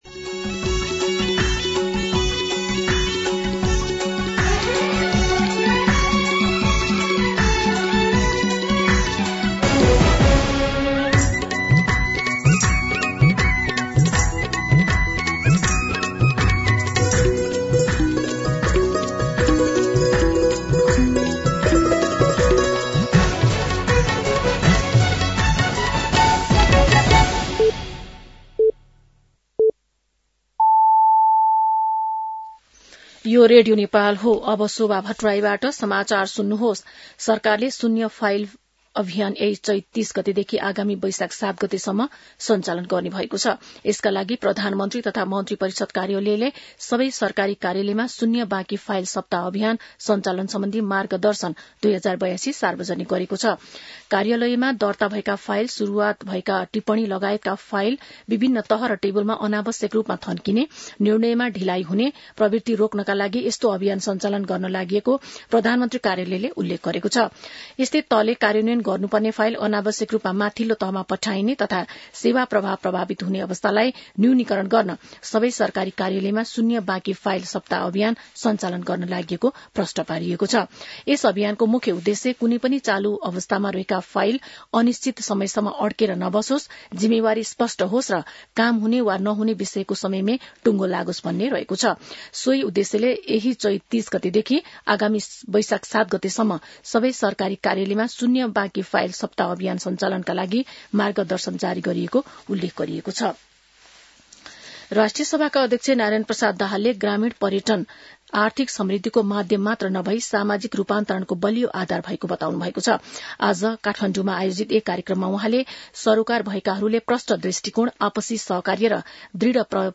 मध्यान्ह १२ बजेको नेपाली समाचार : २८ चैत , २०८२